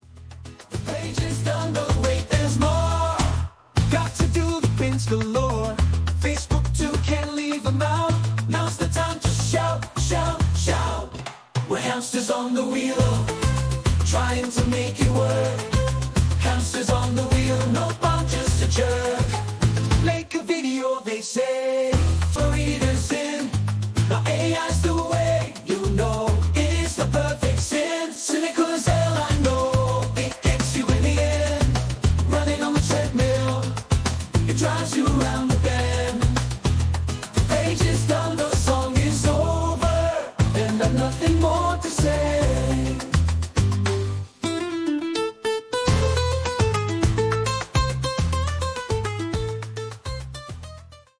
I chose Latin Music, Energetic, Normal, Bass, Acoustic Guitar